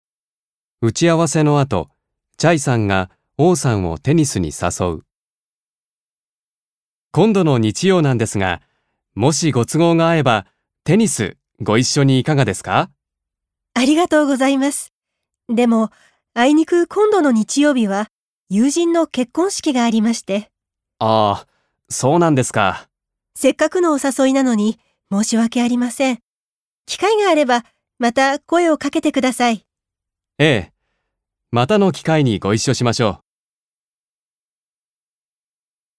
1. 会話（誘いを断る）